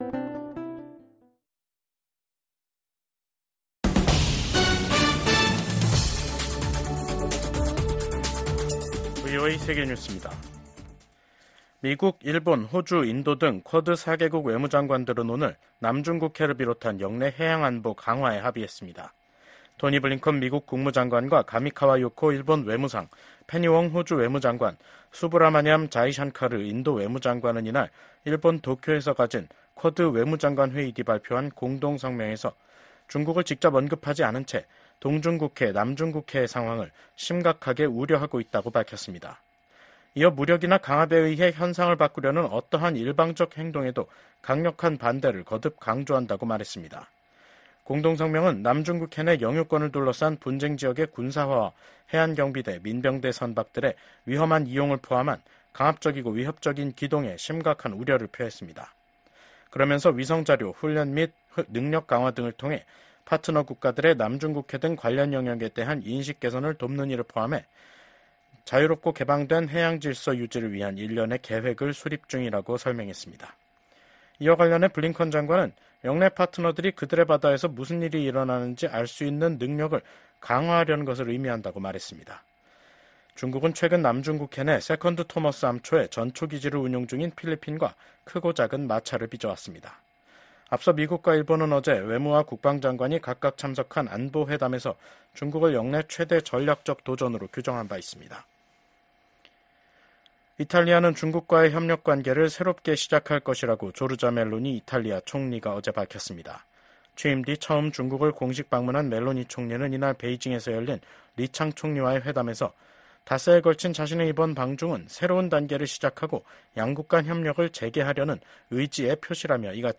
VOA 한국어 간판 뉴스 프로그램 '뉴스 투데이', 2024년 7월 29일 2부 방송입니다. 미국과 한국, 일본이 안보 협력을 제도화하는 문서에 서명했습니다.